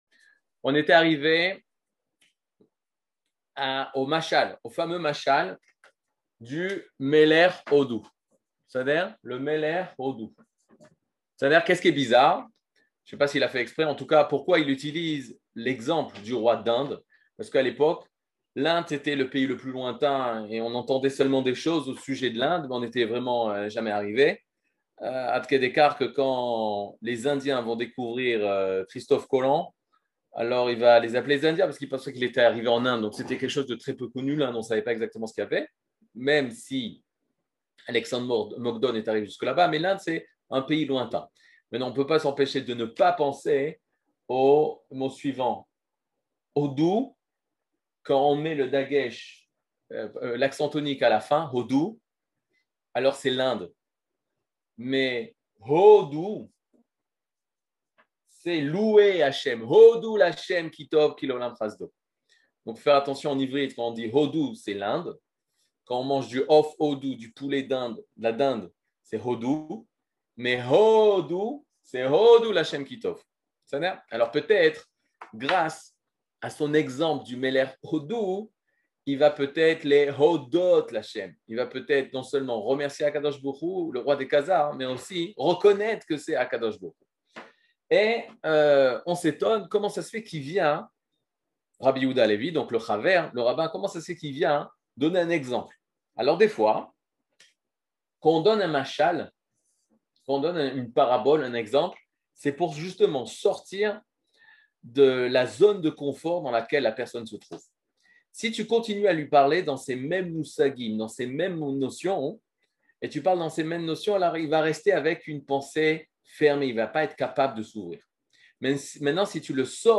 Catégorie Le livre du Kuzari partie 20 00:56:43 Le livre du Kuzari partie 20 cours du 16 mai 2022 56MIN Télécharger AUDIO MP3 (51.92 Mo) Télécharger VIDEO MP4 (121.25 Mo) TAGS : Mini-cours Voir aussi ?